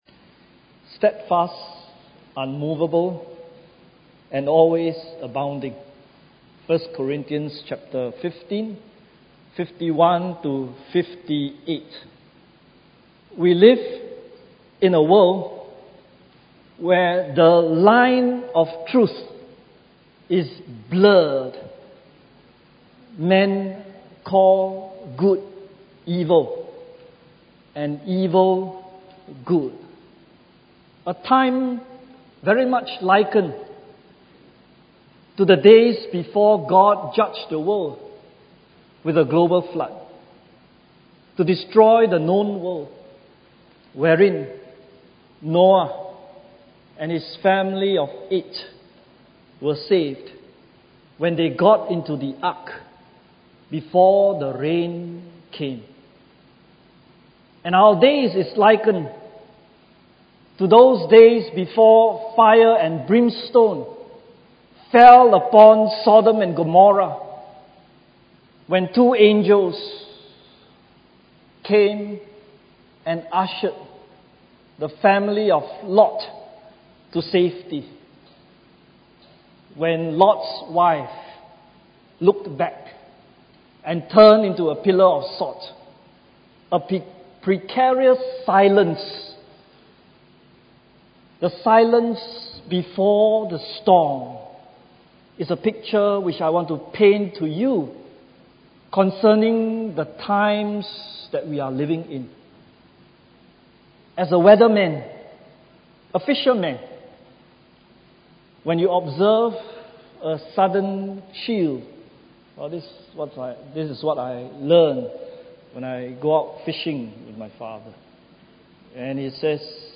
3rd Anniversary Thanksgiving Service – Steadfast, Unmovable and Always Abounding